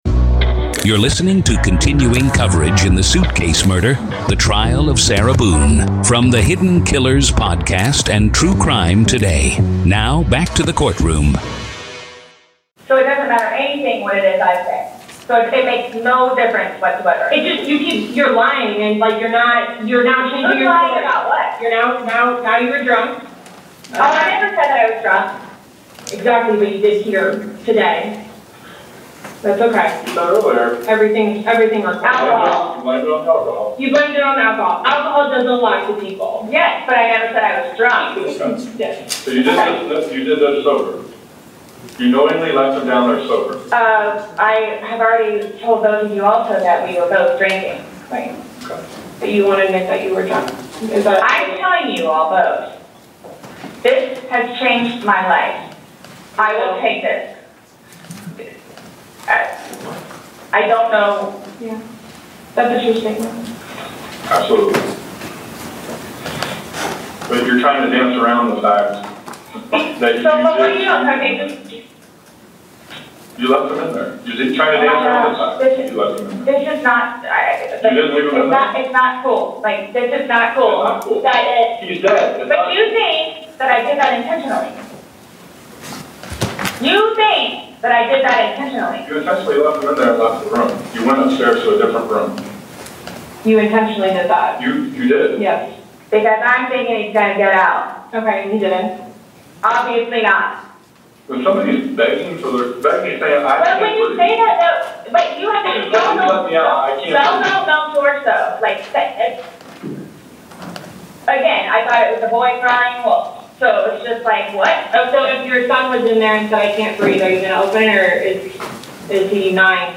RAW COURT AUDIO